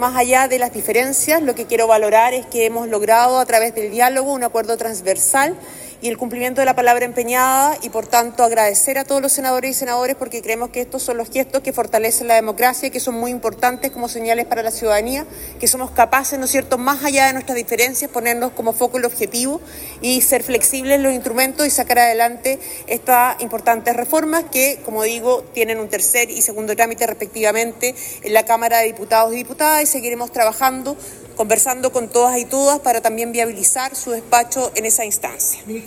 La ministra de la Secretaría General de la Presidencia, Macarena Lobos, valoró la transversalidad del acuerdo y destacó que el Ejecutivo cumplió el compromiso de reponer la sanción.